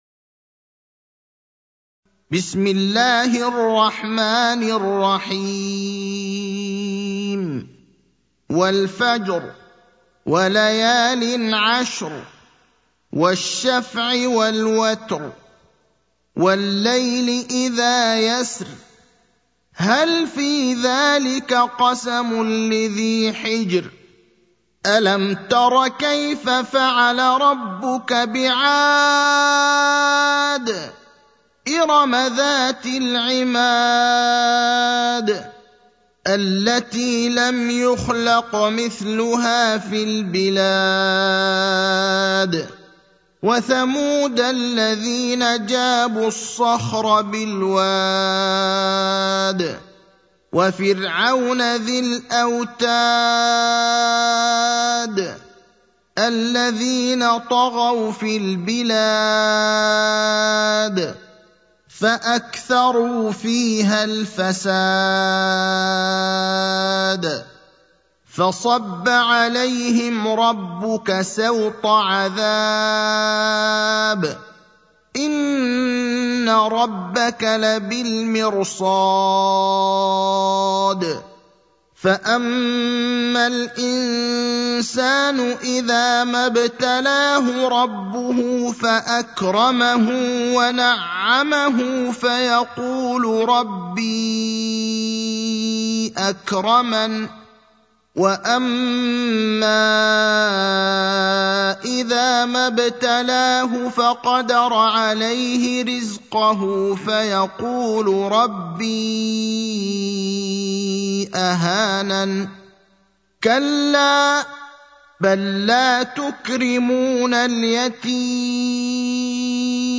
المكان: المسجد النبوي الشيخ: فضيلة الشيخ إبراهيم الأخضر فضيلة الشيخ إبراهيم الأخضر الفجر (89)